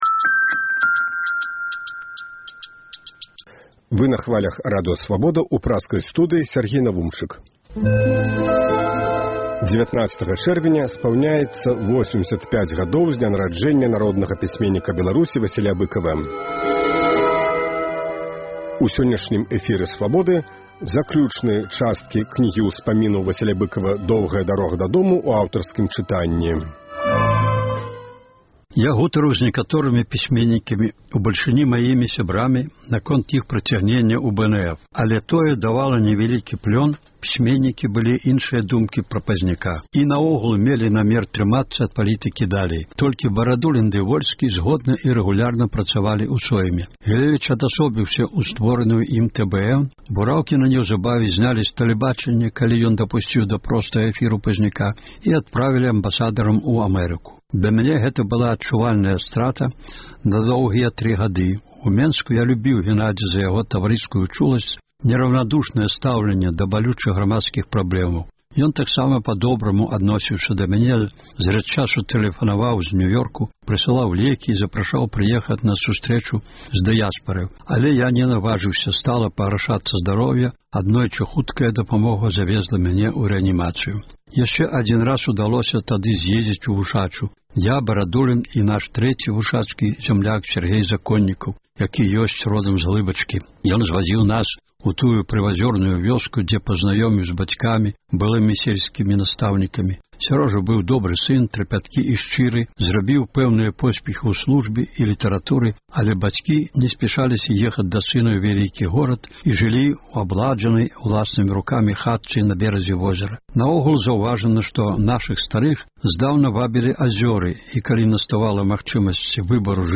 Сёлета ў чэрвені штодня ў нашым эфіры гучыць “Доўгая дарога дадому” ў аўтарскім чытаньні. Сёньня – частка 18-я.